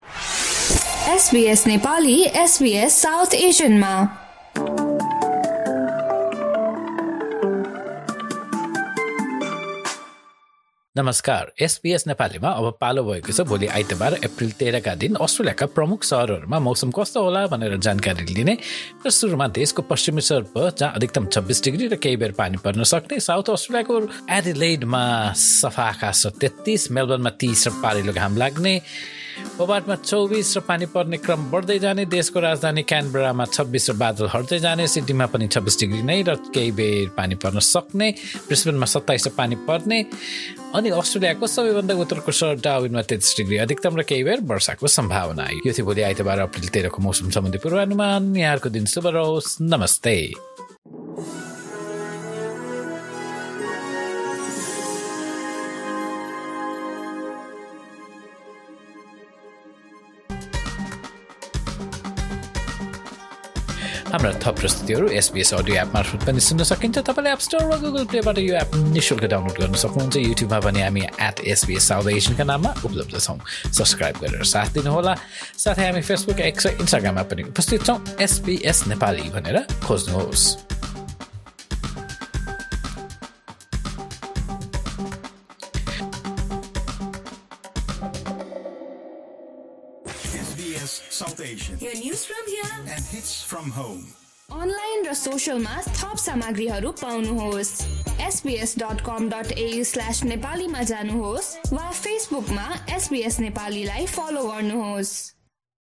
Australian weather update in Nepali for Sunday, 13 April 2025.